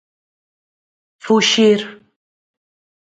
Ler máis Significado (Inglés) to flee to escape Frecuencia B1 Pronúnciase como (IPA) /fuˈʃiɾ/ Etimoloxía (Inglés) Herdado de latín fugere In summary From Old Galician-Portuguese fugir, from Vulgar Latin *fugīre, from Latin fugere.